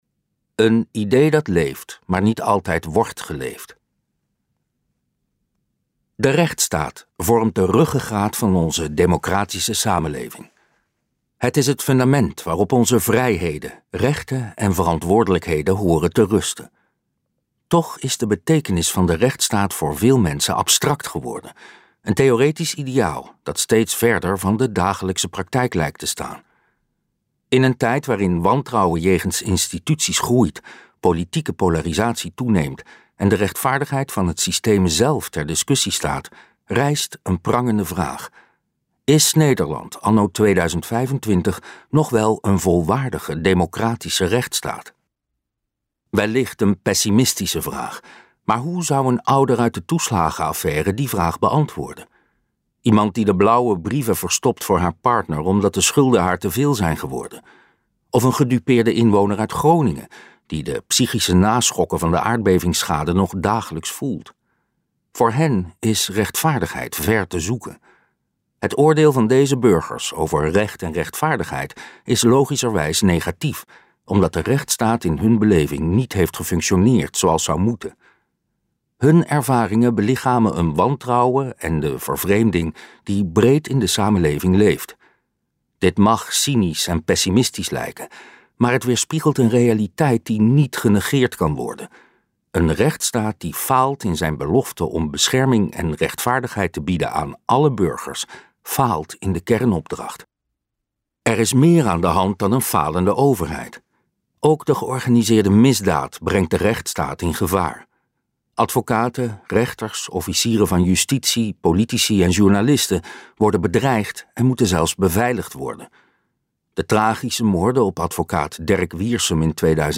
Als de rechtsstaat faalt luisterboek | Ambo|Anthos Uitgevers